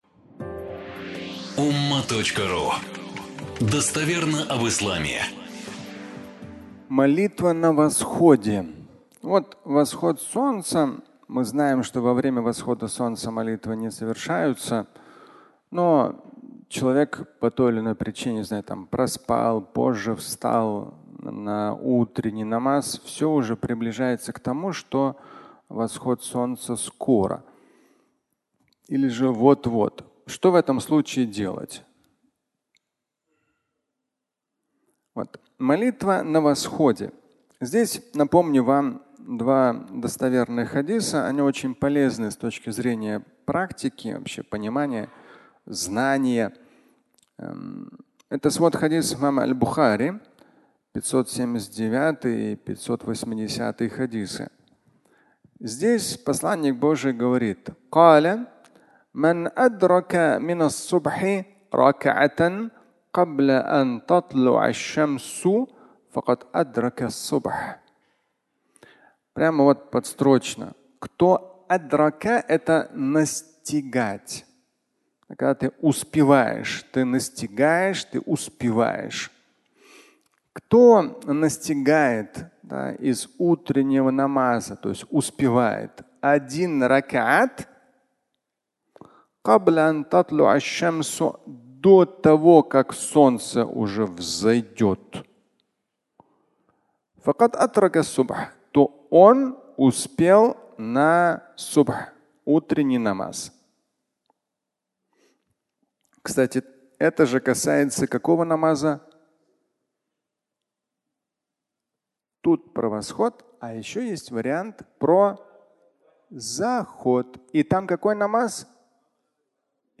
Молитва на восходе (аудиолекция)